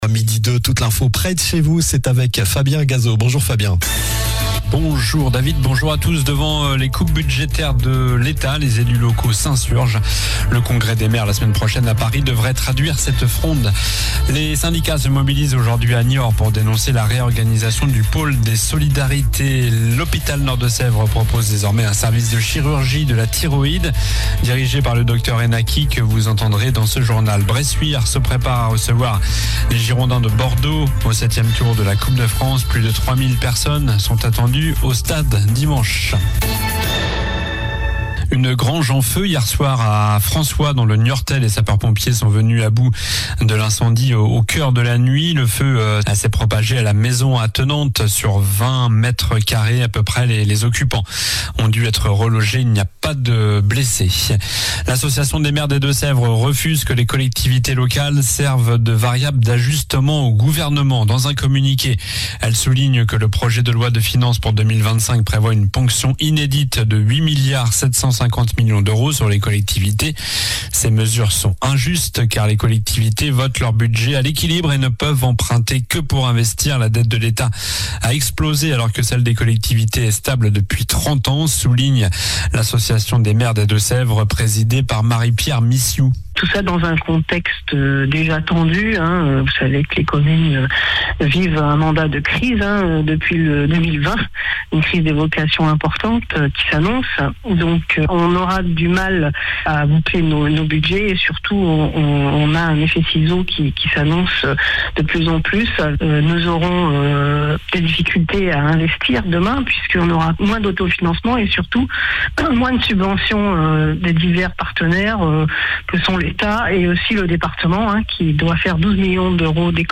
Journal du 14 novembre (midi)